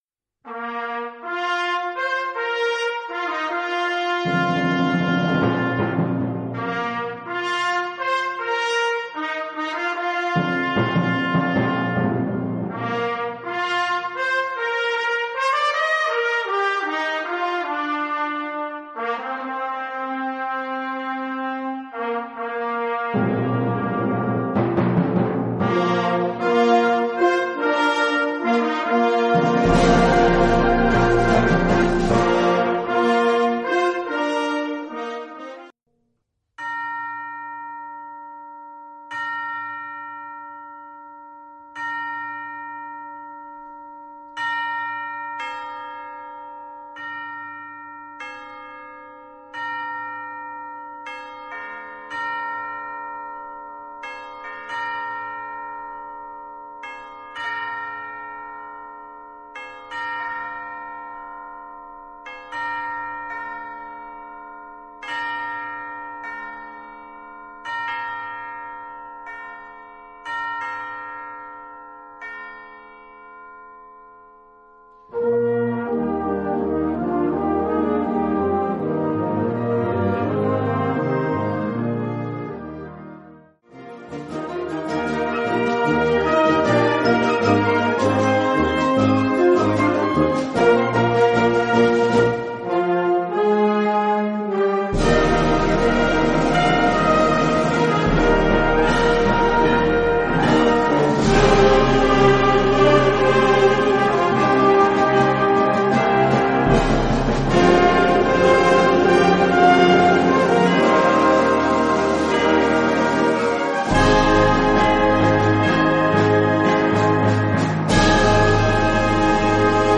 Unterkategorie Suite
Besetzung Ha (Blasorchester)